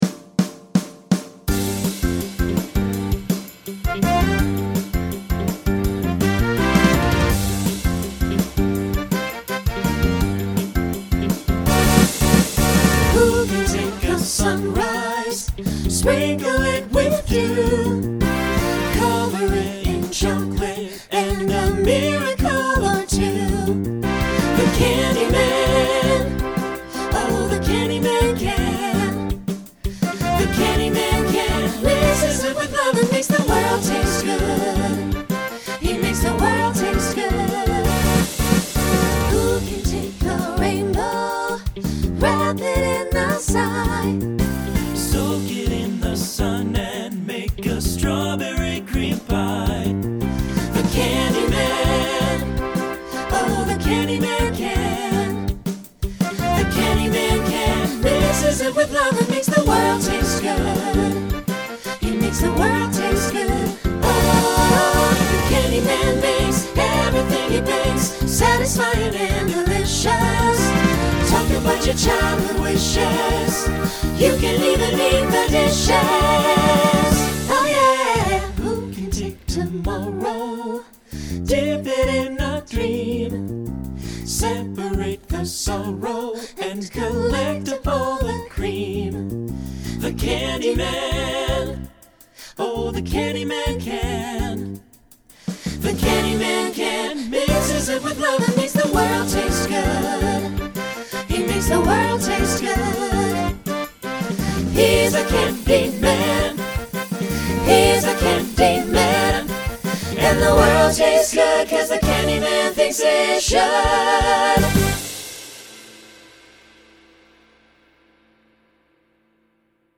Genre Broadway/Film , Pop/Dance Instrumental combo
Voicing SATB